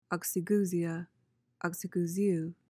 PRONUNCIATION:
(ok-see-GOO/GYOO-zee/zhee-uh, -zhuh)